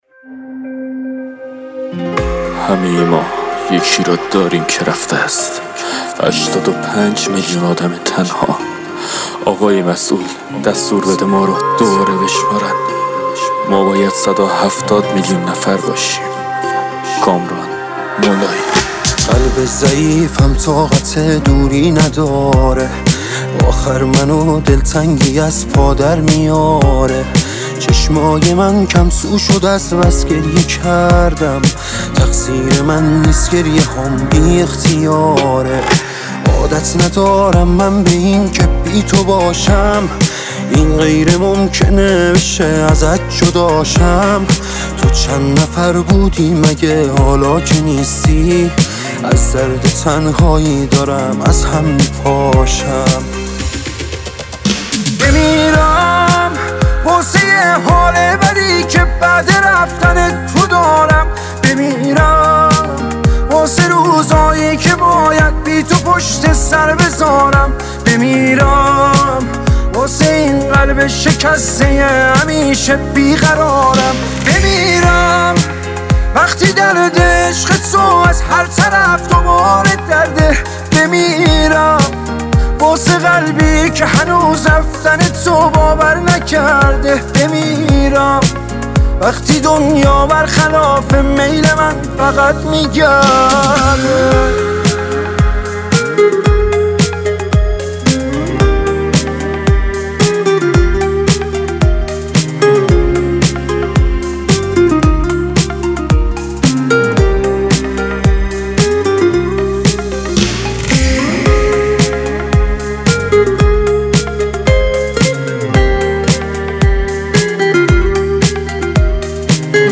اهنگ غمگین سوزناک